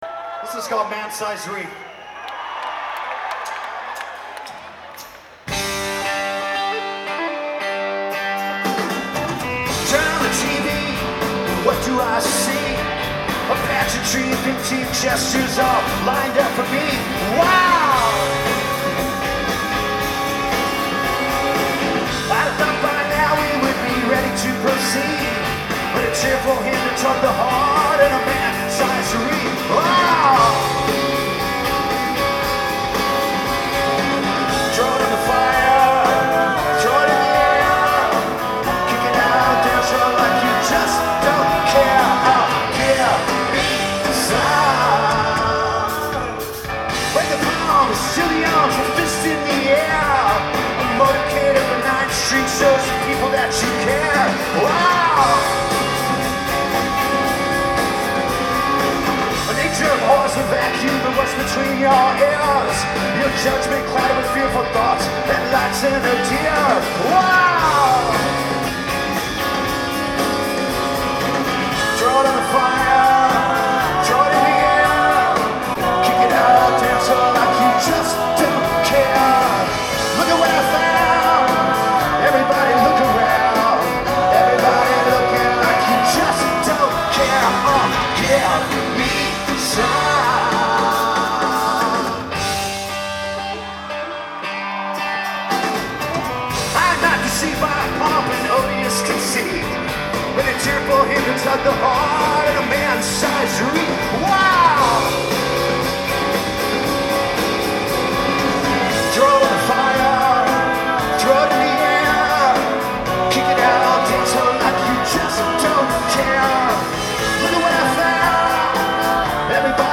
Live at at the Comcast Center